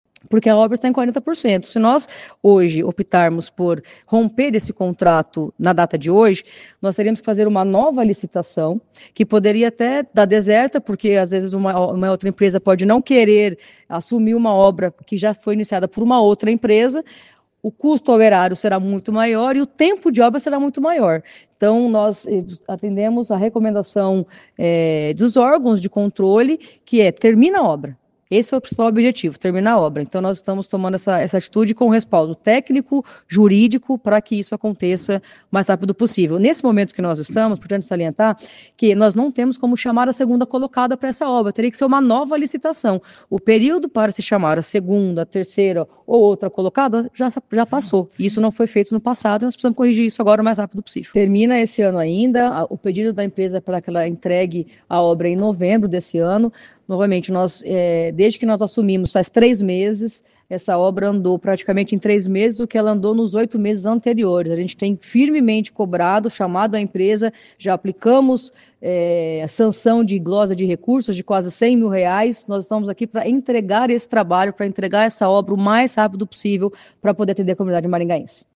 A presidente da Câmara Municipal de Maringá, Majô, em entrevista coletiva, explicou por que a presidência da Casa decidiu prorrogar o contrato com a empresa contratada para a reforma e ampliação do prédio do Legislativo municipal.